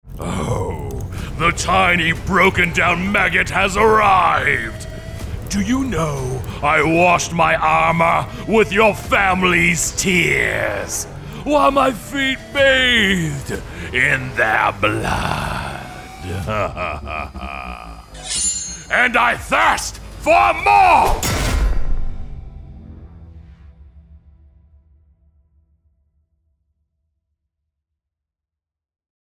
English (American)
Video Games
I use a WA-47jr FET Condenser Microphone, with a Scarlett Solo interface by Focusrite. I have a pop filter, mic screen all in a treated room.